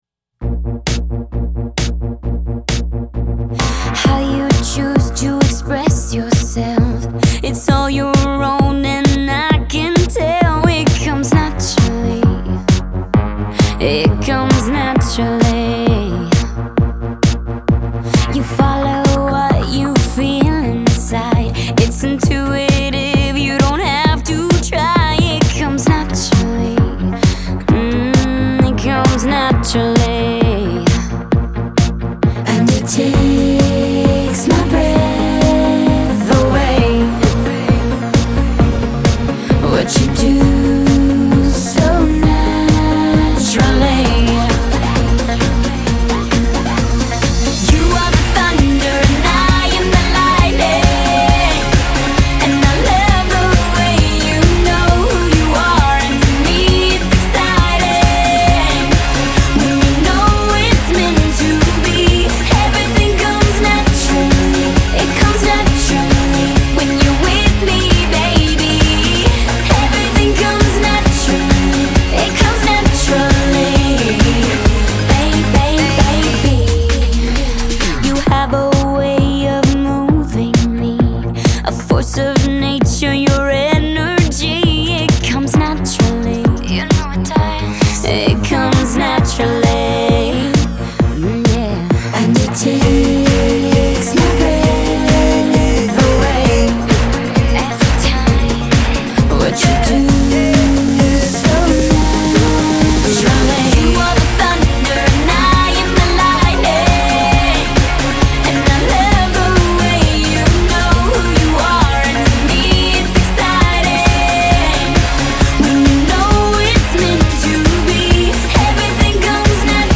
Категория: Попсовые песни